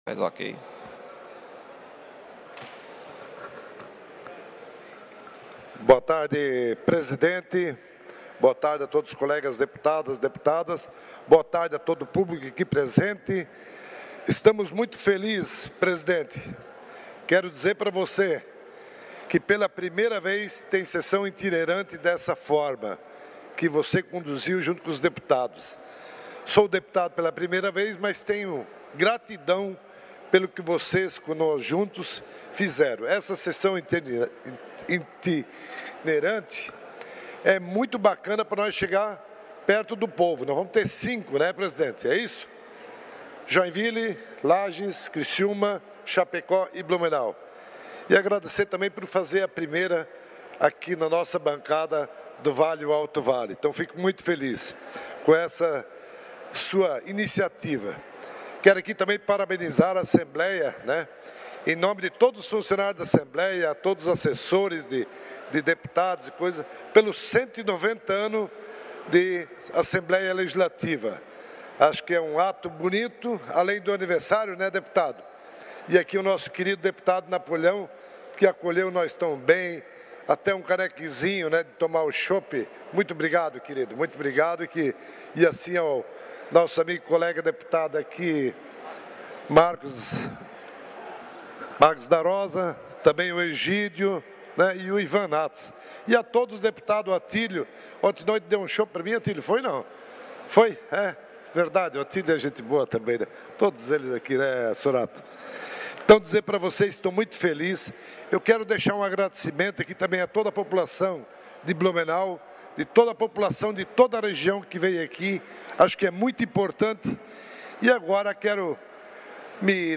Pronunciamentos dos deputados na sessão ordinária desta quarta-feira (08)